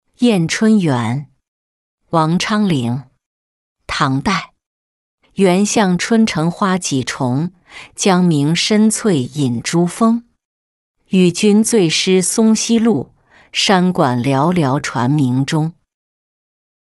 宴春源-音频朗读